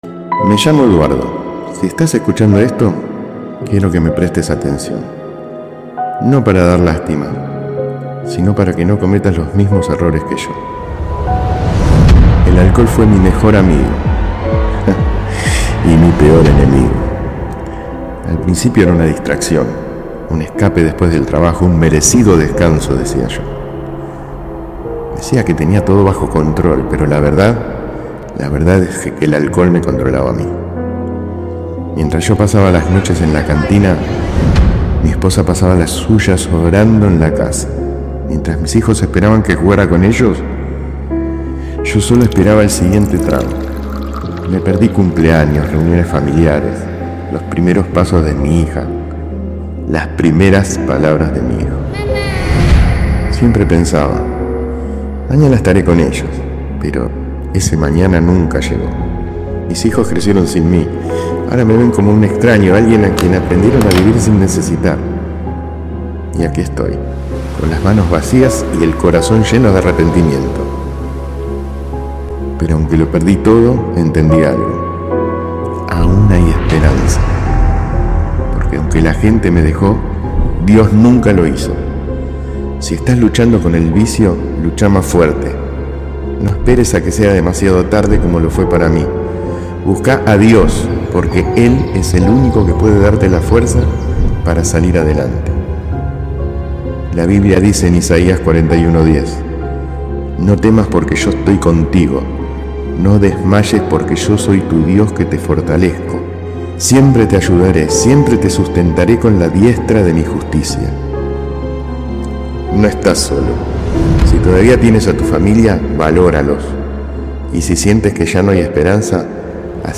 testimonio impactante de un hombre